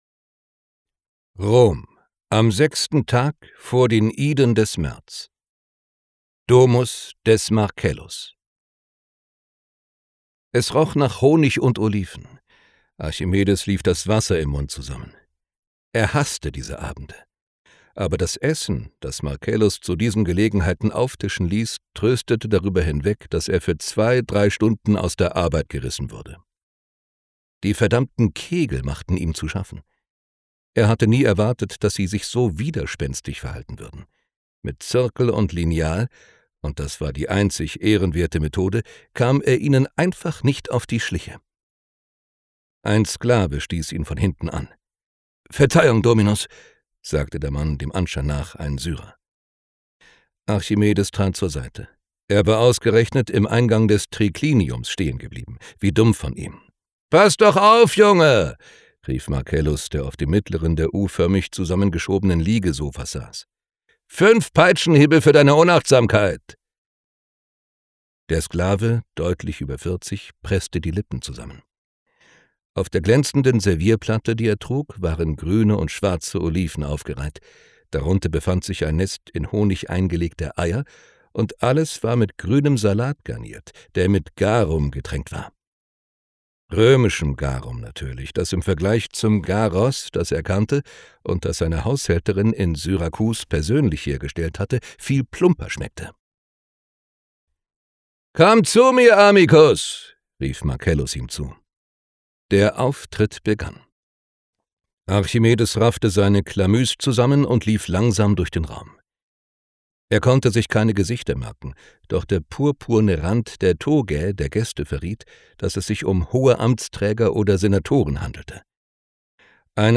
Gekürzt Autorisierte, d.h. von Autor:innen und / oder Verlagen freigegebene, bearbeitete Fassung.
Hörbuchcover von Das archimedische Raumschiff: Ankunft